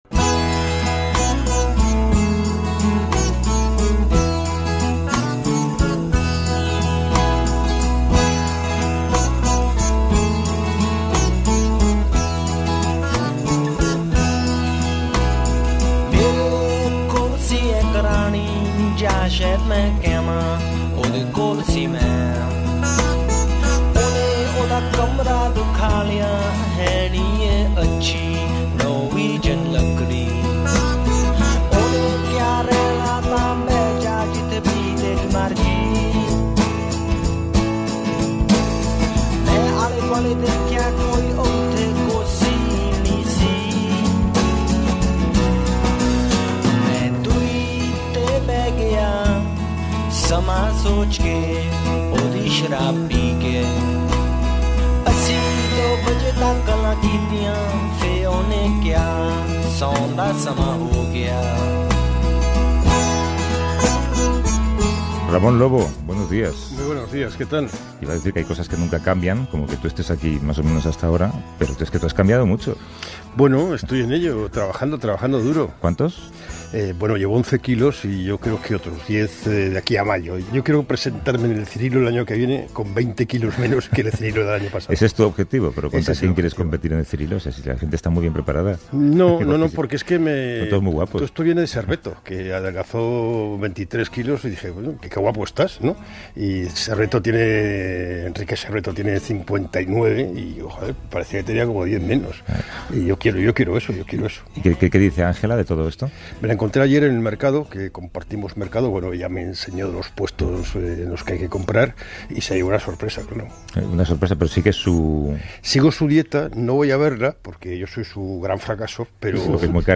El domingo pasado Javier del Pino me invitó al plató de «A vivir que son dos días» para hablar sobre la denuncia que ha hecho el presidente Trump al respecto de que los algoritmos de Google y las redes sociales falsifican la realidad para inclinar la balanza a favor de los políticos de izquierda.